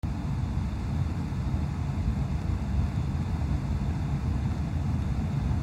Ce que j'appelle bruit parasite, c'est du grésillement...
Voici un fichier avec le grésillement.
Le grésillement réagit lorsque je touche au volume, aux aigus et aux graves
J'entends plutôt un souffle mais je suis sourd à 50%.
J’aurais tendance à ”chipoter” et l’appeler plutôt rumble, qui fait penser à un bruit ”mécanique” comme si la cellule servait de micro qui capte le bruit du moteur.
à l'oreille un bruit blanc, voir les découplage des zeners des préampli: c309;c313,c311,c312.